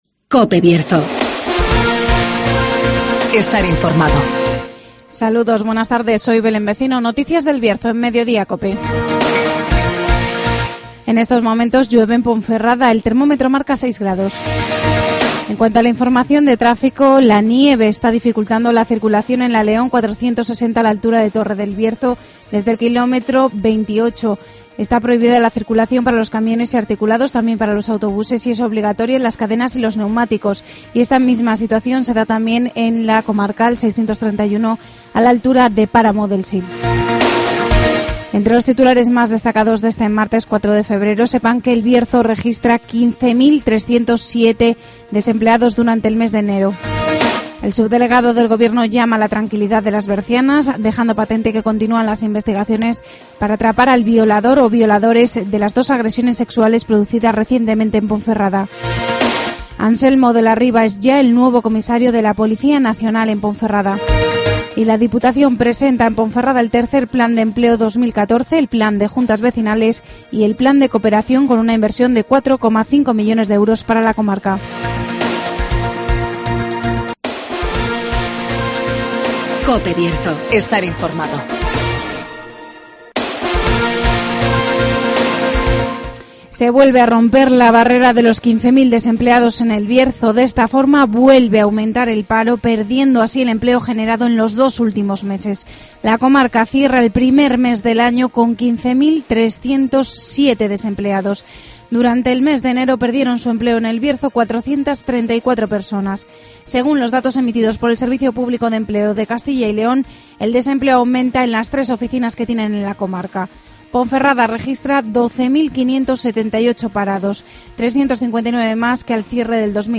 Informativo COPE Bierzo